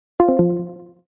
Som de Disconnect Discord
Categoria: Sons de memes
Descrição: Baixar o som de Disconnect Discord, download Discord voice disconnect sound mp3 e muitos outros sons do Discord neste site.
som-de-disconnect-discord-pt-www_tiengdong_com.mp3